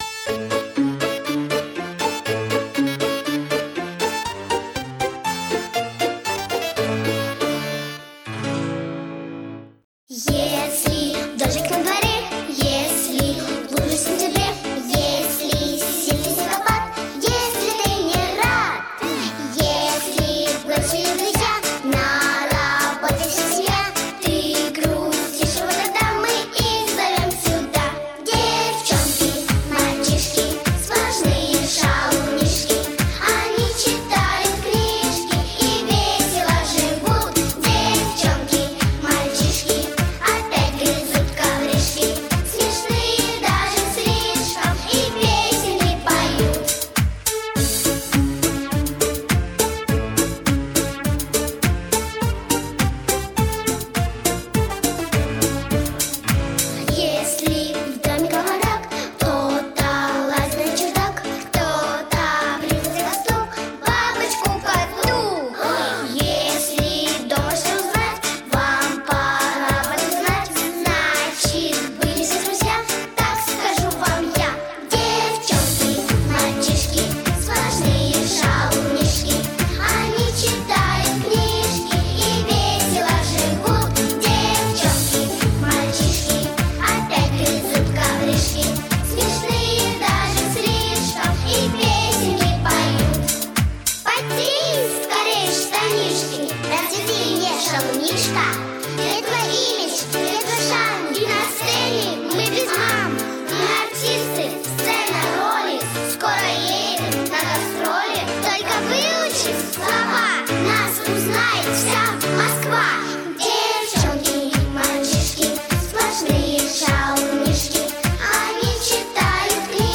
детская песенка